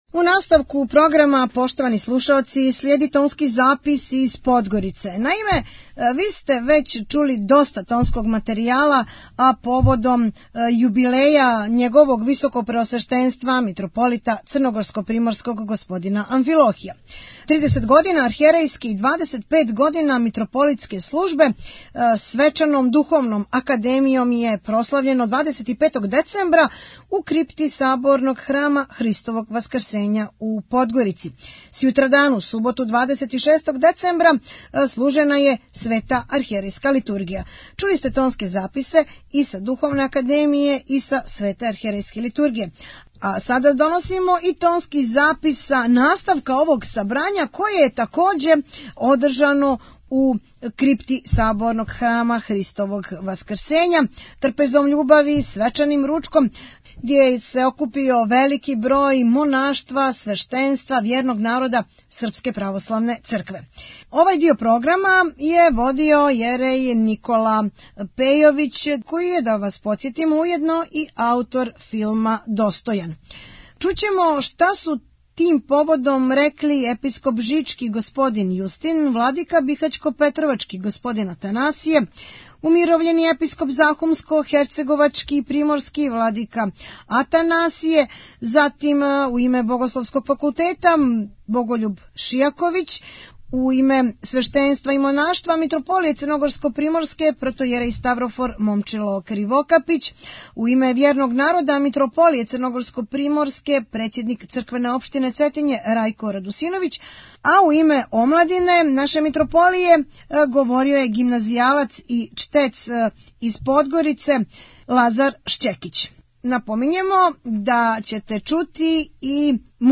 Поводом јубилеја Митрополита Амфилохија у крипти Саборног храма уприличена je свечана трпеза љубави